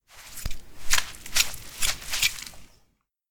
harvest_use.ogg